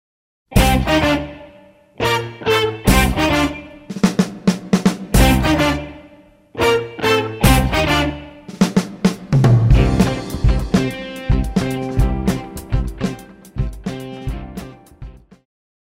爵士
套鼓(架子鼓)
乐团
演奏曲
现代爵士
独奏与伴奏
有节拍器
此曲旋律节奏很容易，
编写时应用了节拍让此曲更有跳动感，
也就是运用大鼓加小鼓的节奏来命名，